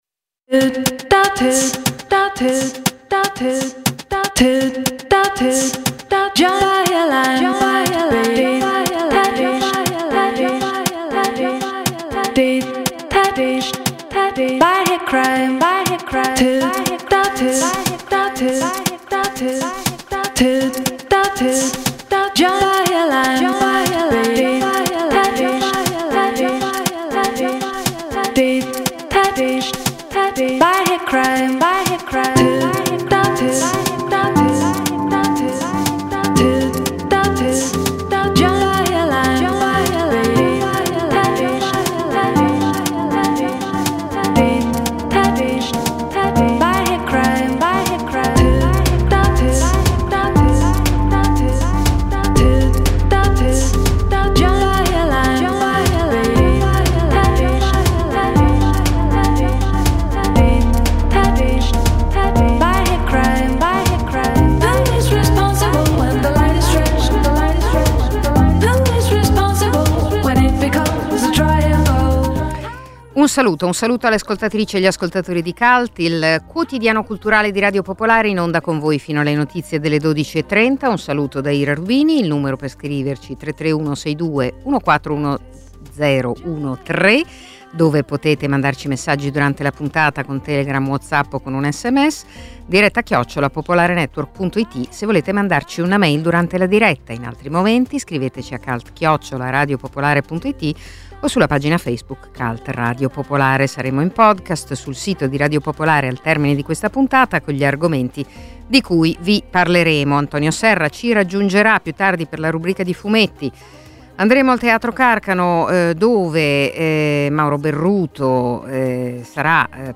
Cult è il quotidiano culturale di Radio Popolare, in onda dal lunedì al venerdì dalle 11.30 alle 12.30.